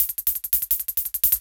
Index of /musicradar/ultimate-hihat-samples/170bpm
UHH_ElectroHatC_170-03.wav